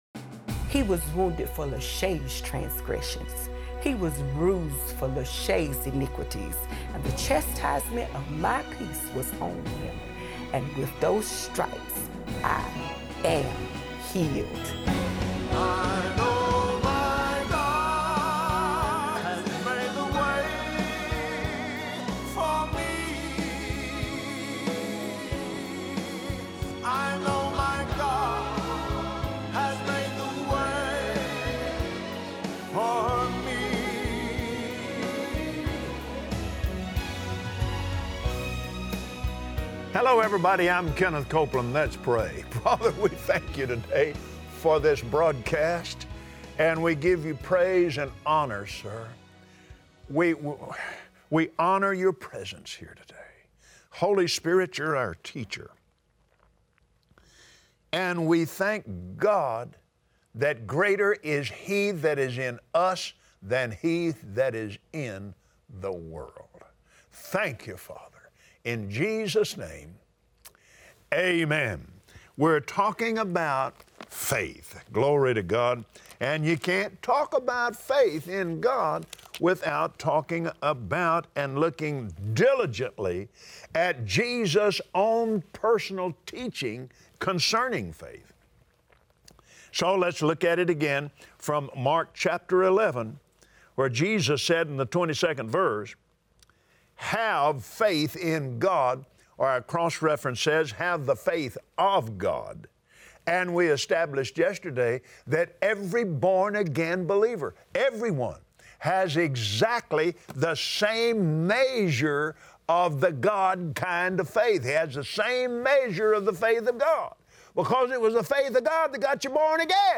Believers Voice of Victory Audio Broadcast for Tuesday 08/01/2017 Keep feeding on a steady diet of faith, and see the results! Watch Kenneth Copeland on Believer’s Voice of Victory explain how your words activate victory in your life, and your words are the result of what you feed your spirit.